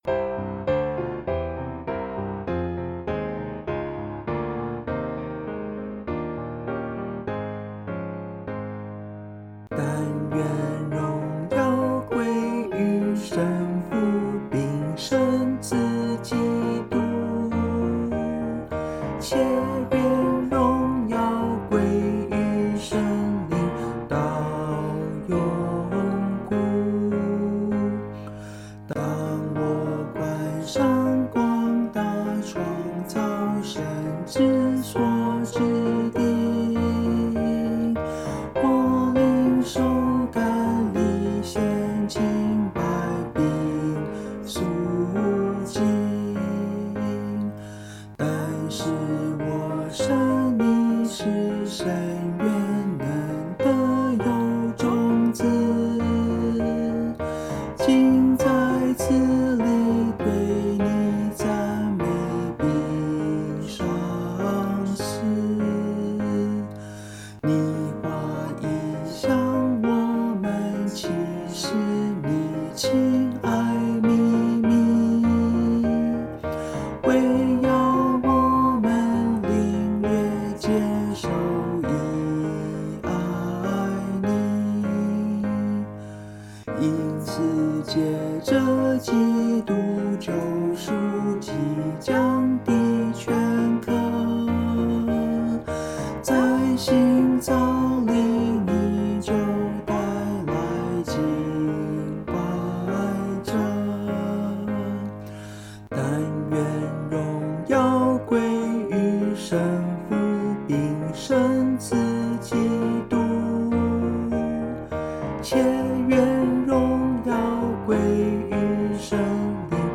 Ab大调